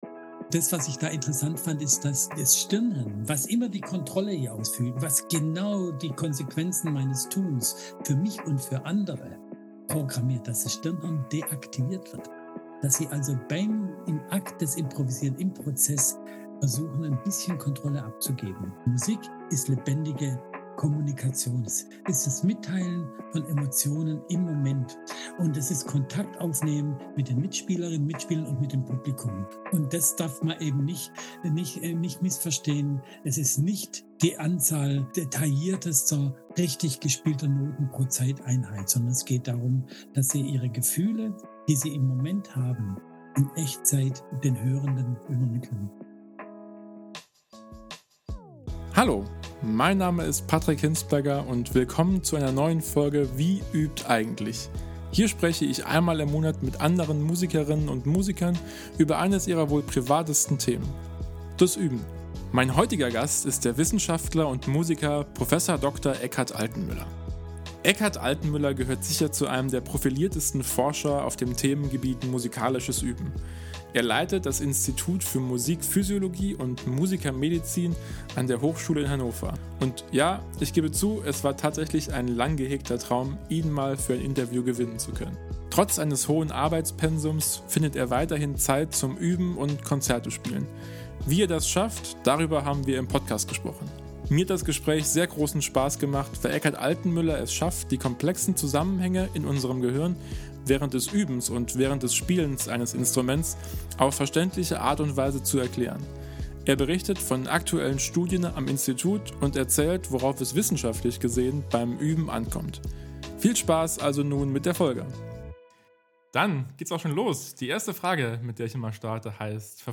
Und ja, ich gebe zu, es war tatsächlich ein lang gehegter Traum ihn mal für ein Interview gewinnen zu können.